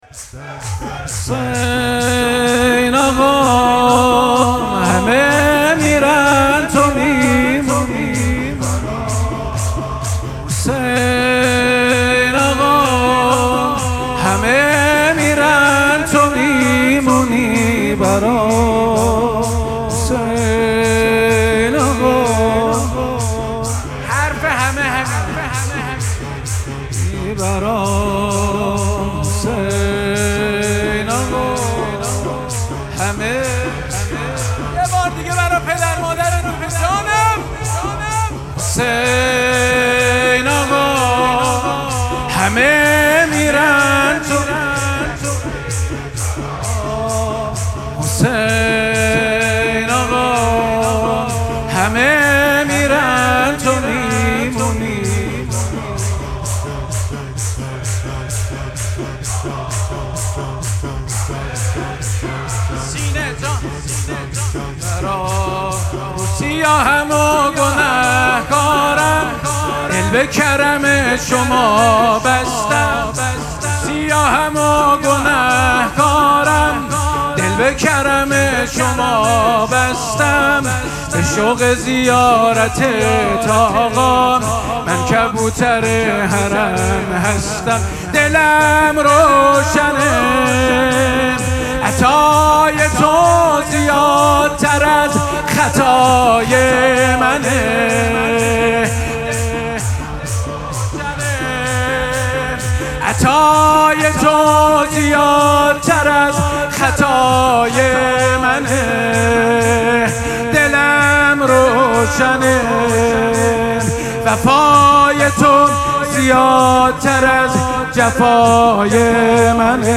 مراسم مناجات شب بیستم ماه مبارک رمضان
حسینیه ریحانه الحسین سلام الله علیها
شور